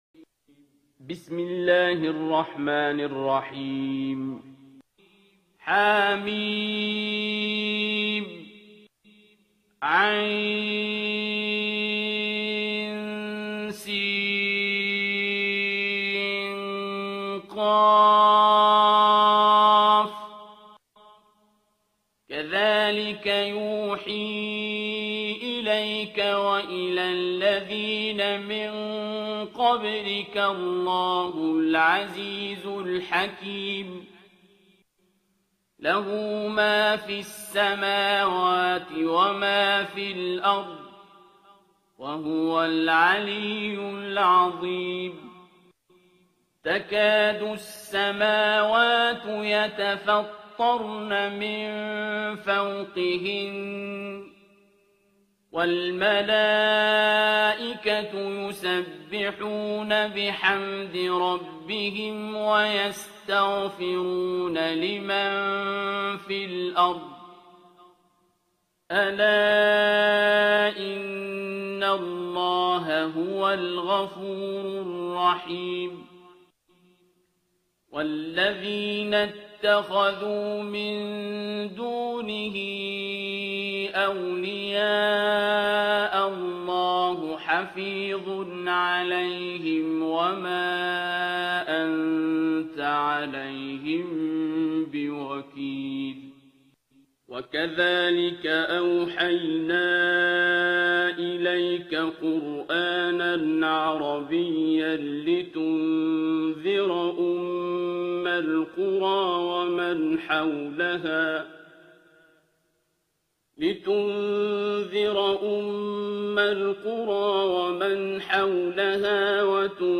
ترتیل سوره شوری با صدای عبدالباسط عبدالصمد
042-Abdul-Basit-Surah-Ash-Shura.mp3